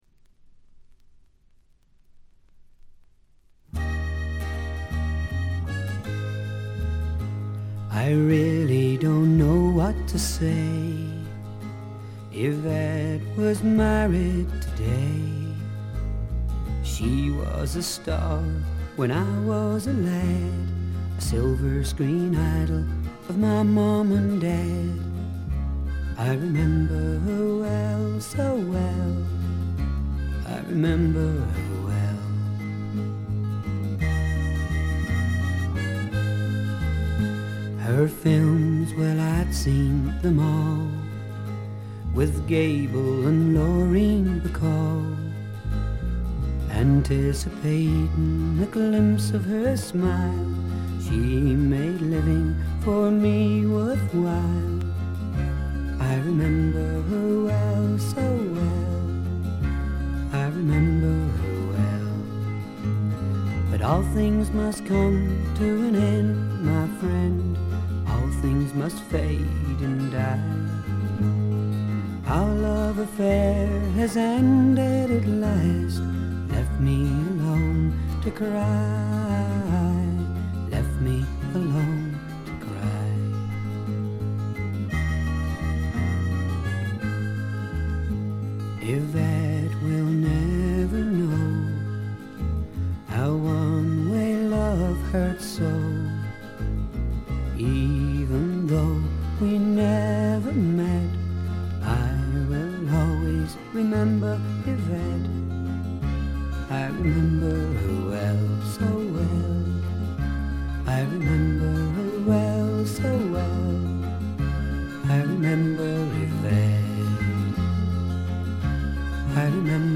これ以外はところどころでチリプチ。
英国のフォーキーなシンガー・ソングライター
弾き語りに近いような控えめでセンスの良いバックがつく曲が多く、優しい歌声によくマッチしています。
試聴曲は現品からの取り込み音源です。